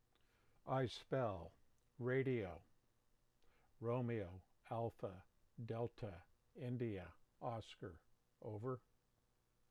Example: the word RADIO will be spelled out as, ROMEO, ALPHA, DELTA, INDIA, OSCAR.